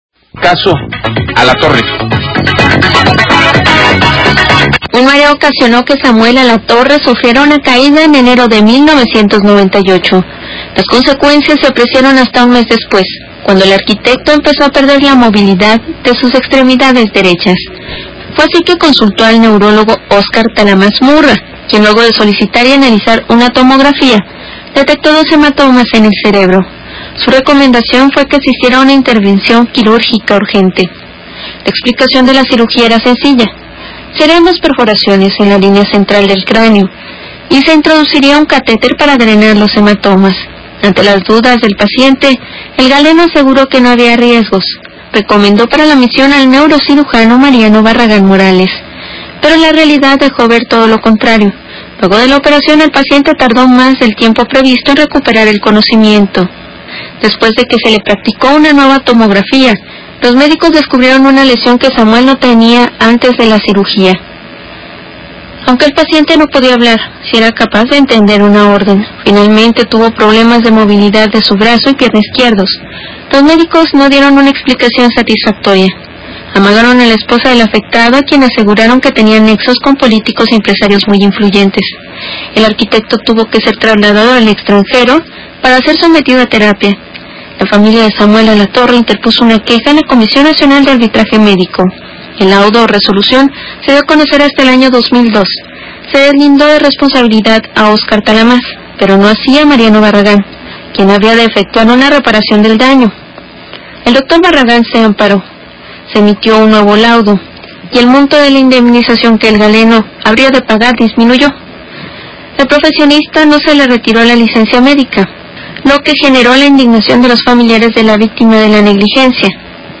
R A D I O